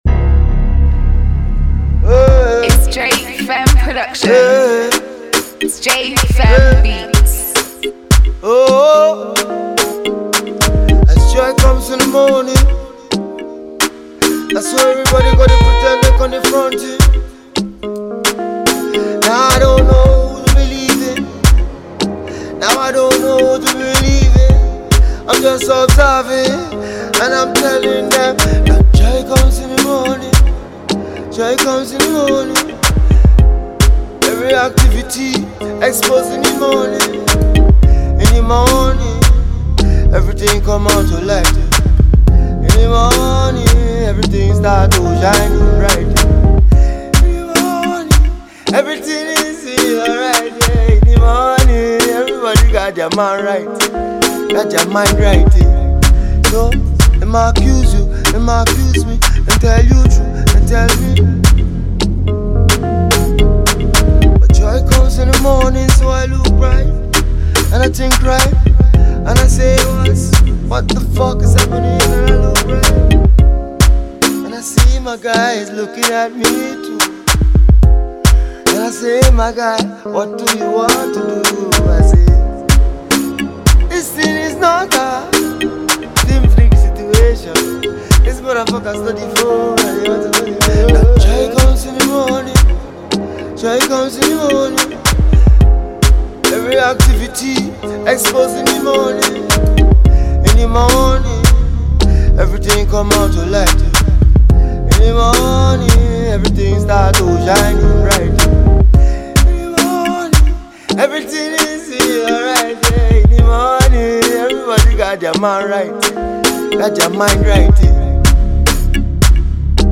The song is a freestyle, check it out below.